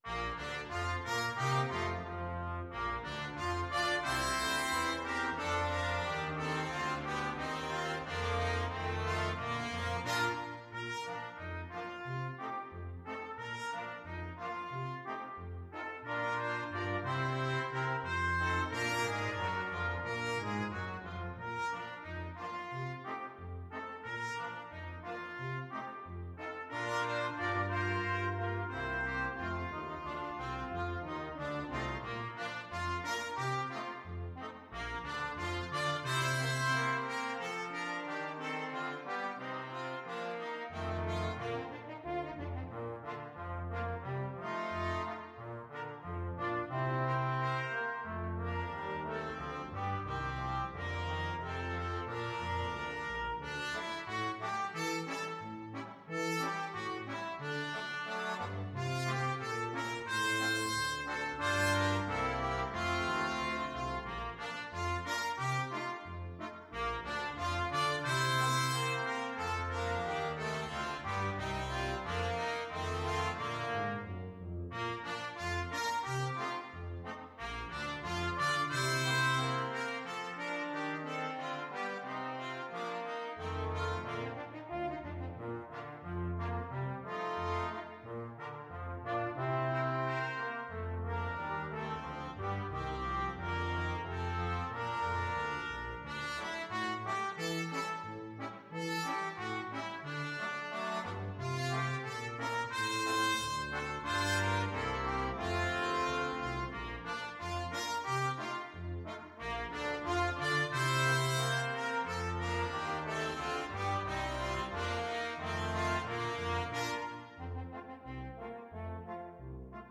Trumpet 1Trumpet 2French HornTromboneTuba
=90 Fast and cheerful
2/2 (View more 2/2 Music)
Brass Quintet  (View more Intermediate Brass Quintet Music)
Pop (View more Pop Brass Quintet Music)